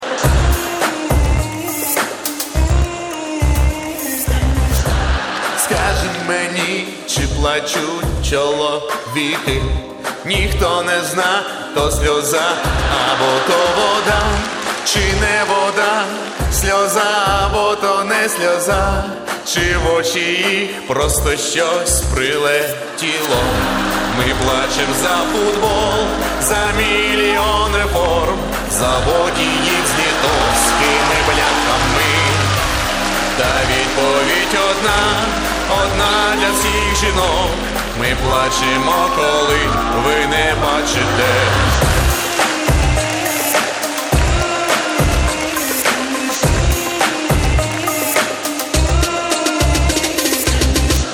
Категория: Смешные реалтоны